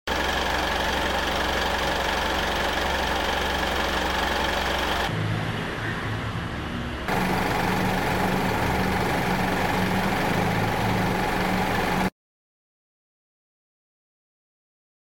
TURN VOLUME UP! The difference of Ravenol Oil! Smoother engine noise = Less friction = Healthier Engine Tested and Proven!